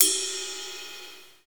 drum-hitwhistle.mp3